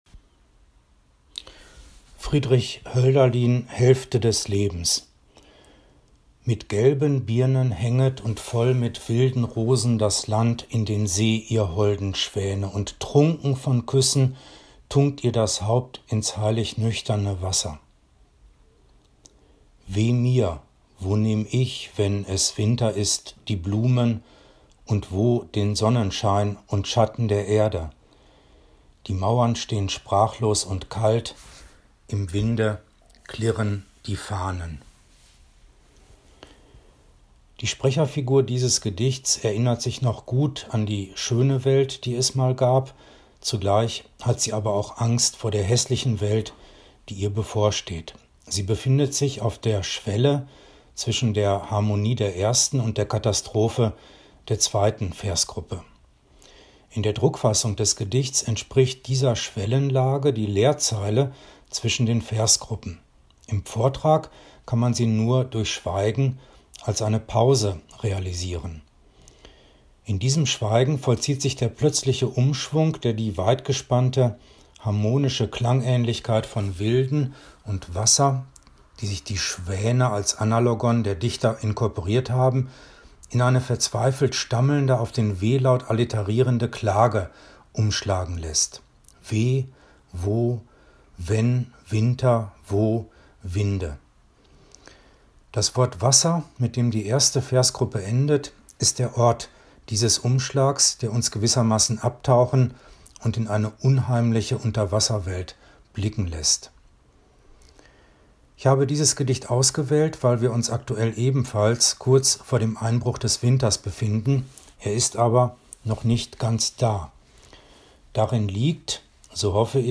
Wir haben Gedichte aufgenommen, die wir aus dem ein oder anderen Grund mögen, und Sie können sich unsere Aufnahmen anhören, an jedem Tag bis Weihnachten eine andere.